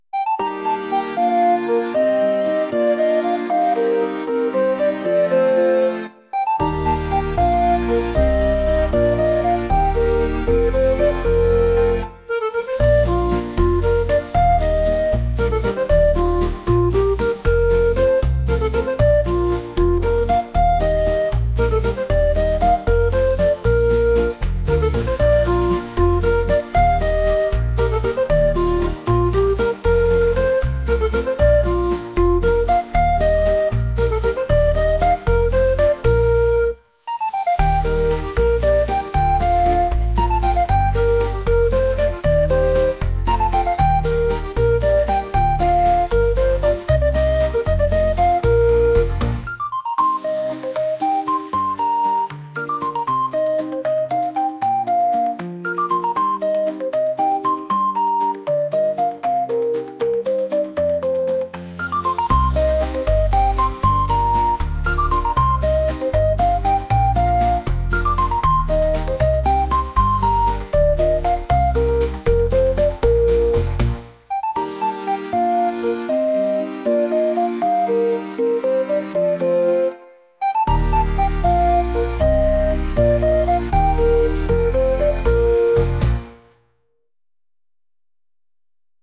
川沿いを歩くような爽やかなリコーダー曲です。